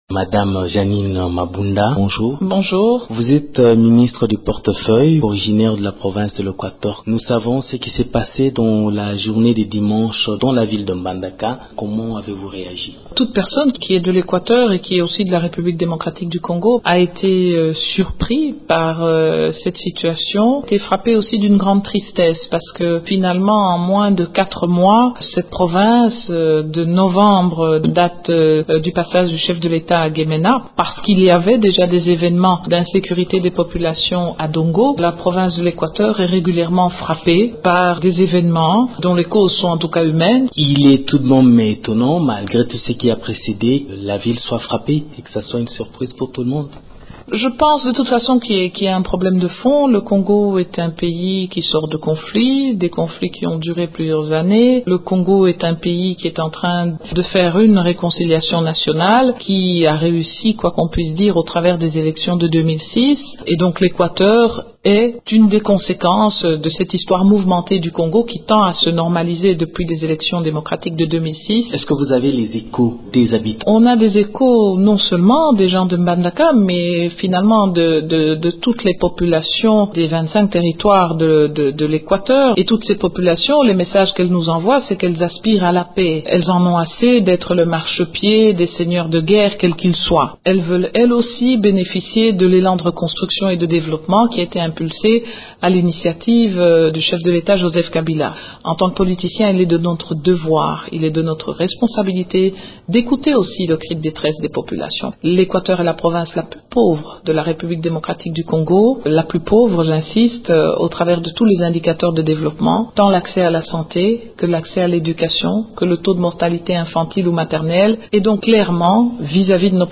Déclaration lundi de Jeannine Mabunda, ministre du Porte-feuille originaire de Équateur, au cours d’un entretien avec Radio Okapi. Sa réaction fait suite aux récents troubles dans cette province.